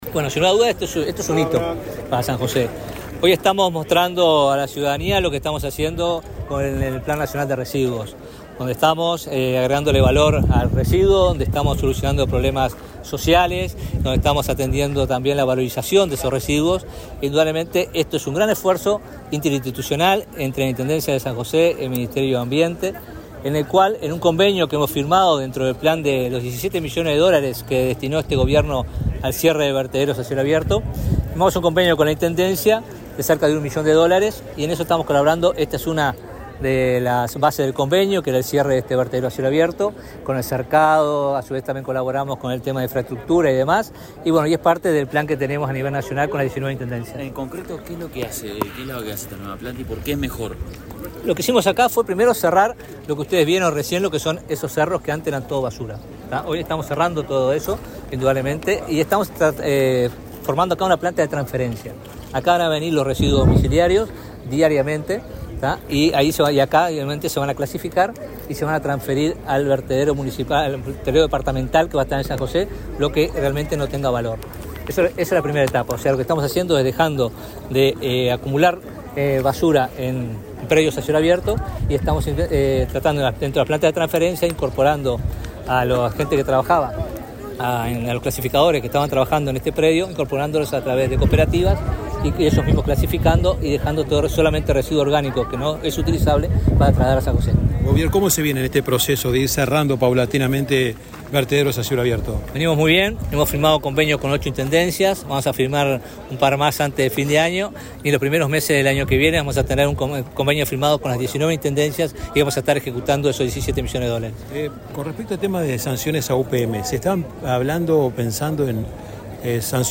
Declaraciones del ministro de Ambiente, Robert Bouvier
Luego, dialogó con la prensa.